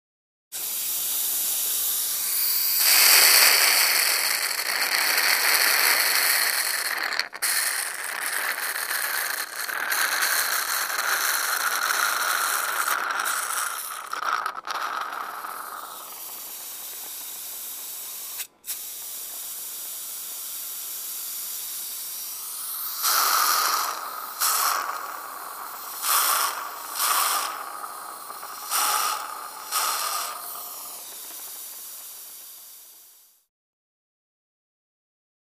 DentistSpitVacuum PE269501
Dentist Spit Vacuum; Dentist Spit Vacuum; On / Hiss / Slurp / Gurgle; Close Perspective. Suction.